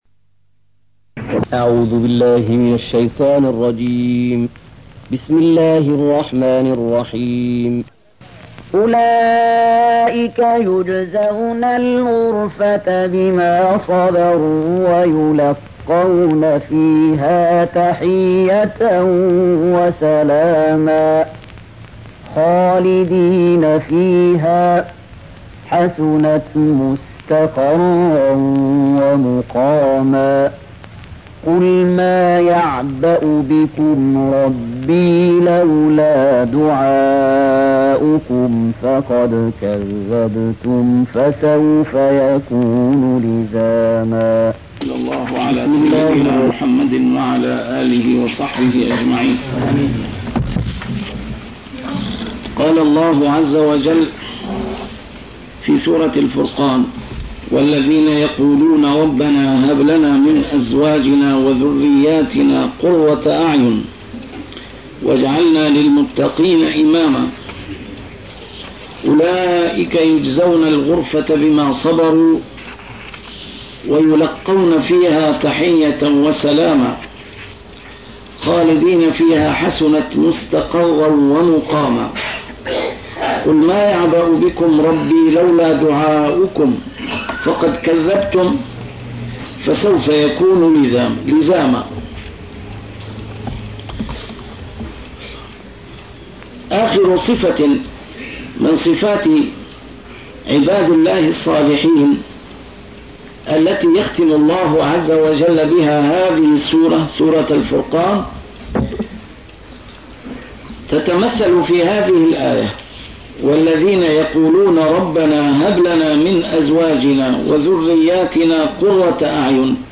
A MARTYR SCHOLAR: IMAM MUHAMMAD SAEED RAMADAN AL-BOUTI - الدروس العلمية - تفسير القرآن الكريم - تسجيل قديم - الدرس 224: الفرقان 75-77